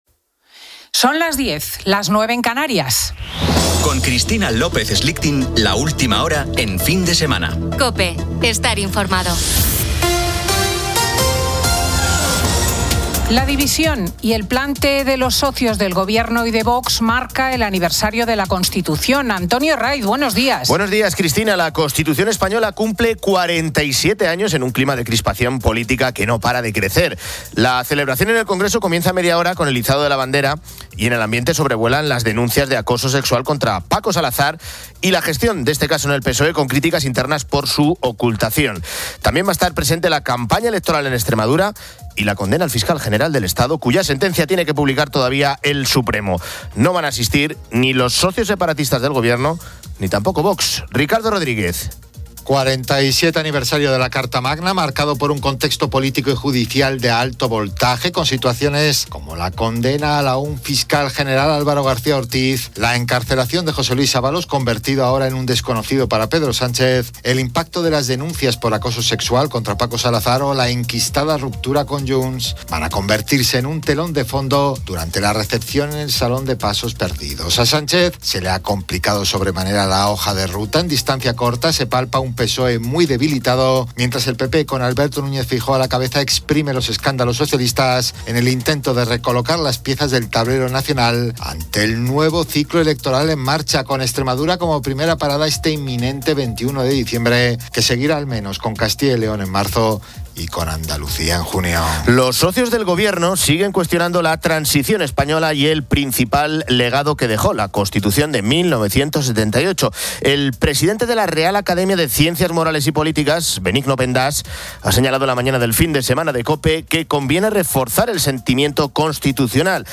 Fin de Semana 10:00H | 06 DIC 2025 | Fin de Semana Editorial de Cristina López Schlichting en el Día de la Constitución. Antonio Jiménez repasa con Cristina la actualidad de la semana.